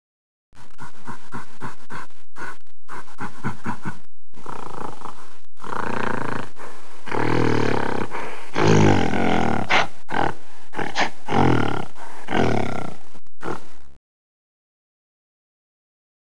Click on the Beanie Baby picture to hear the sound the Beanie Animal would make in the wild !!!
gorilla.wav